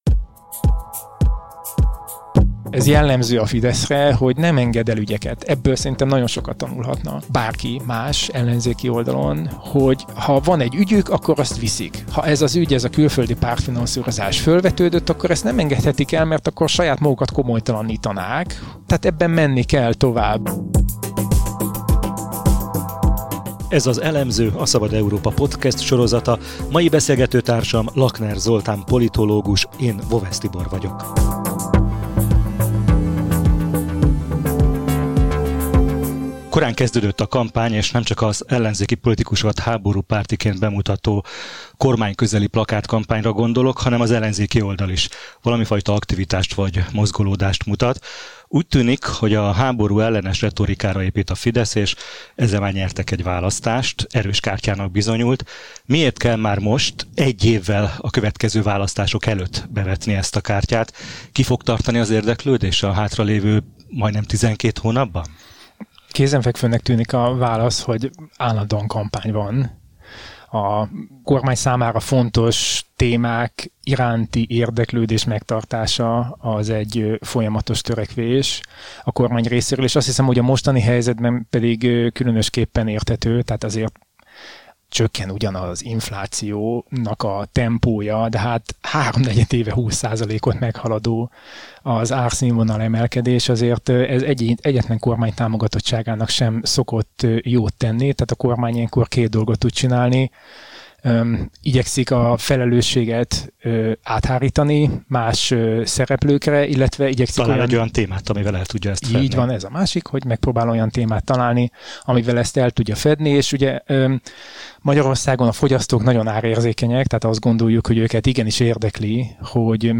A frakcióbüdzsék lefelezése volt az első csapás, az ÁSZ-bírság a második lépés lehet az ellenzéki pártok kampány-lehetőségeinek szűkítésére, mondja a politológus podcastunkban. Beszélgettünk arról, hogy a DK vezető, de nem domináló párt, és hogy van-e esélye a kormányszóvivőnek Budapesten.